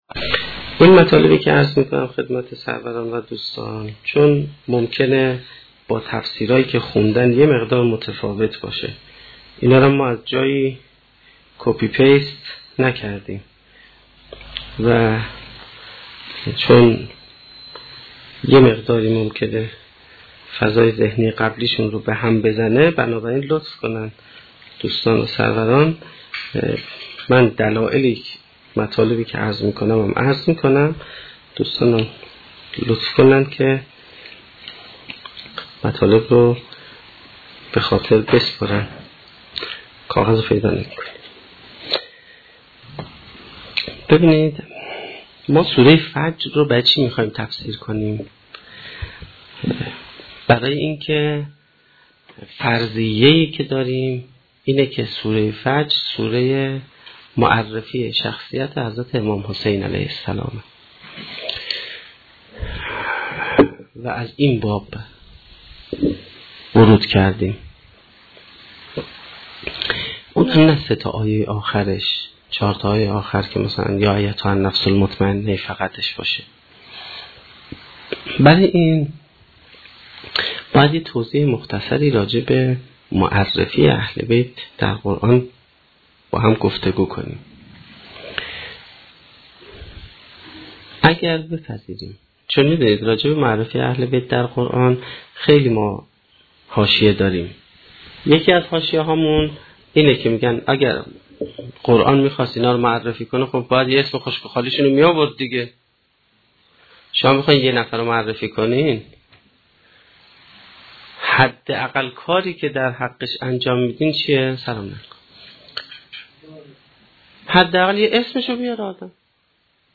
سخنرانی چهارمین شب دهه محرم1435-1392